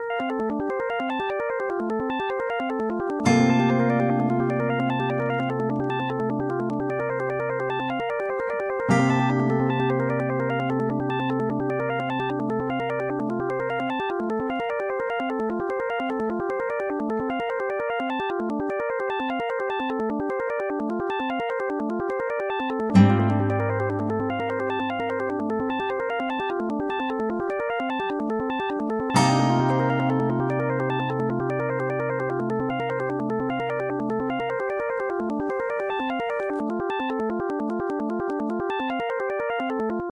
描述：简短的三场例子，只用正弦波随机产生声音的云。
标签： CSound的 粒状 PMASK 正弦 随机
声道立体声